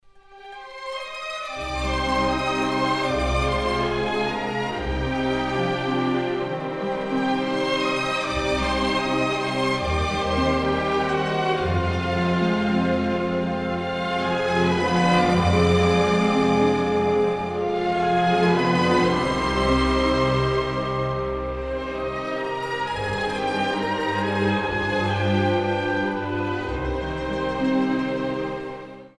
саундтреки
инструментальные